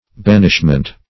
Banishment \Ban"ish*ment\, n. [Cf. F. bannissement.]